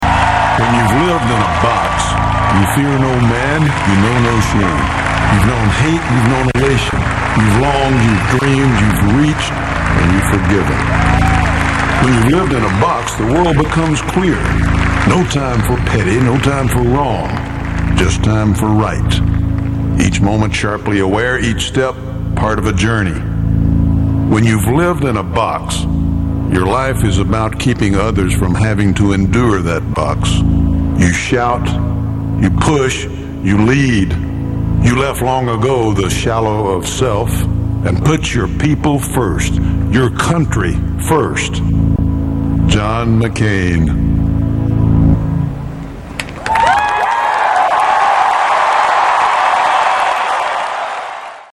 Tags: John McCain John McCain speech Republican national convention Republican John McCain clips